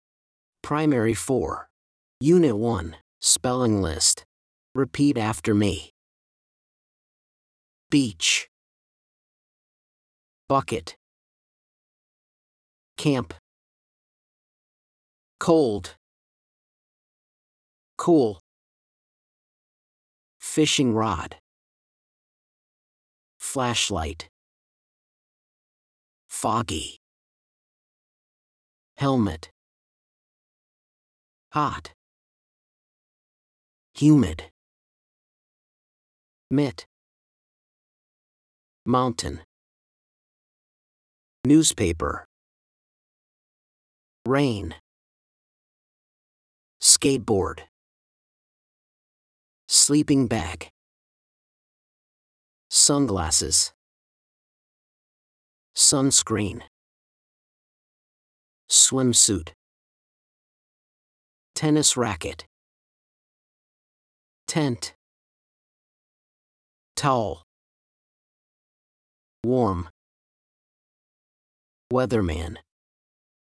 SPELLING LIST FOR UNIT 1
blankListen to the teacher: